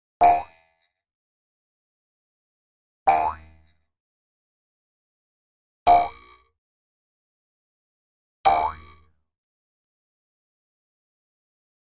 Для разнообразия (звук морчанга)